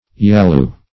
Yalu \Ya"lu\, prop. n.